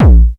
Techno Kick 01.wav